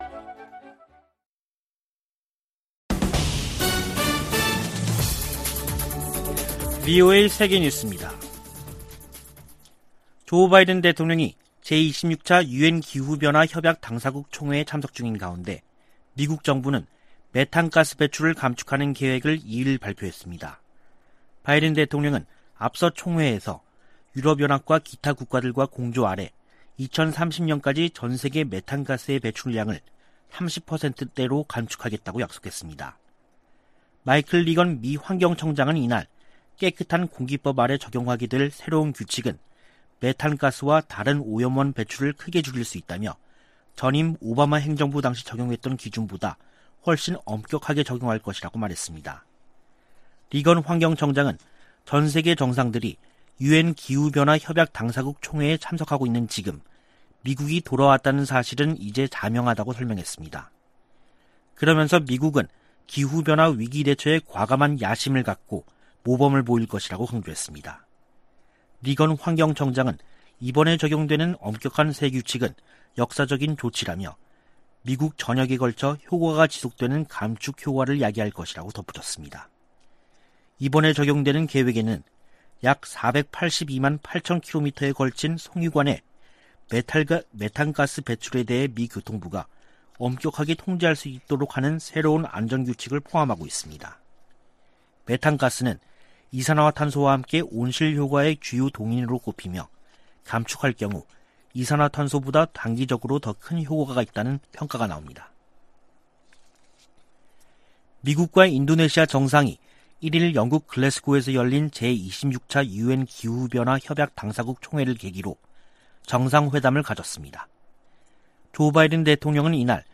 VOA 한국어 간판 뉴스 프로그램 '뉴스 투데이', 2021년 11월 2일 2부 방송입니다. 중국과 러시아가 유엔 안전보장이사회에 대북제재 완화를 위한 결의안 초안을 다시 제출했습니다.